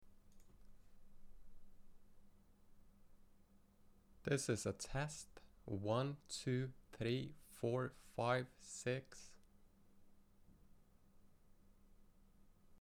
Ok, Could you attach an mp3 of the static, please?
Please do this with no effects or processes - Just a raw recording.